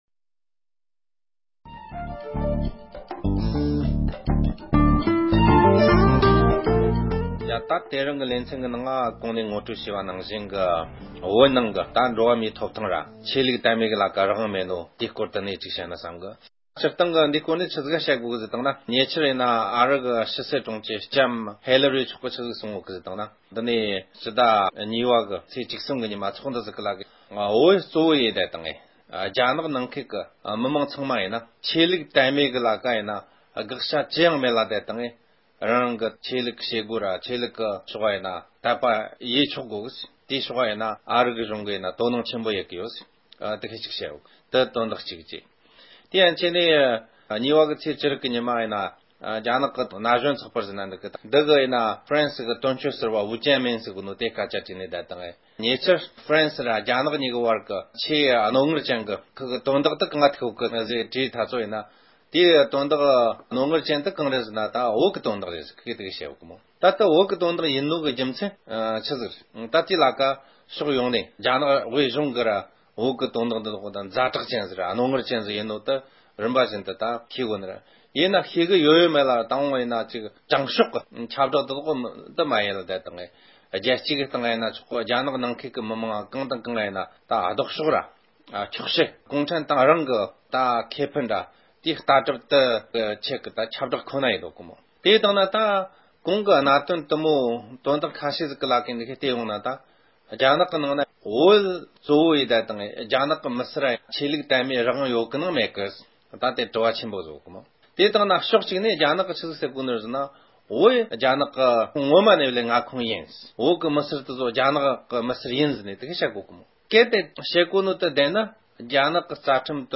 རྒྱ་ནག་གཞུང་གིས་རྒྱལ་ཁབ་ཀྱི་ཁྲིམས་ལུགས་དང་རྒྱབ་འགལ་གྱི་བོད་མིའི་ཆོས་དད་རང་དབང་ཧམ་འཕྲོག་བྱས་ཡོད་པའི་དཔྱད་གཏམ།